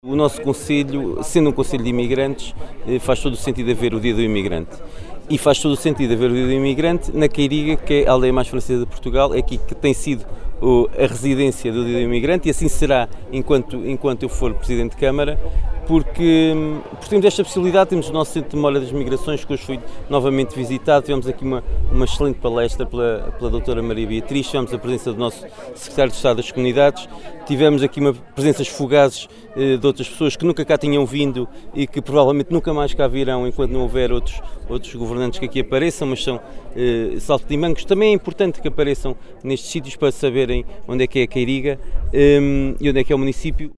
Paulo Marques, Presidente do Município, disse que, sendo Vila Nova de Paiva um concelho de emigrantes, faz todo o sentido comemorar “O Dia do Emigrante”, em especial, na Queiriga “a aldeia mais Francesa de Portugal“.